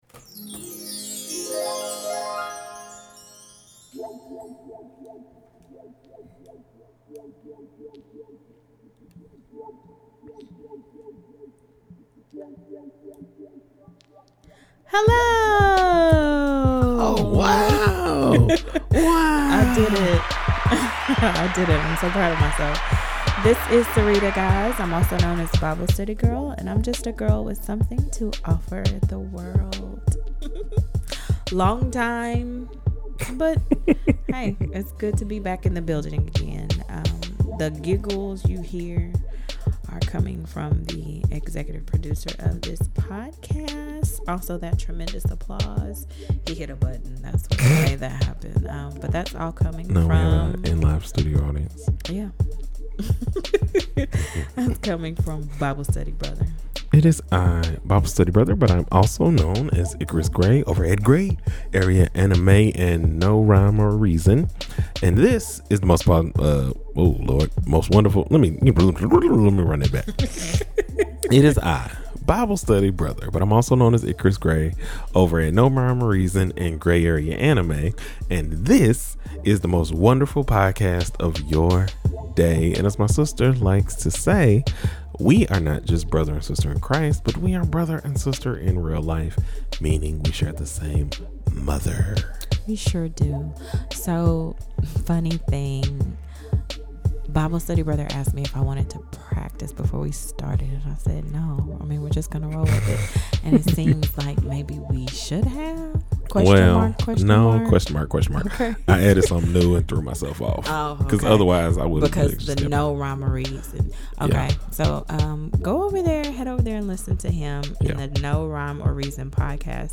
Lots of laughs….lots of tangents….lots of love.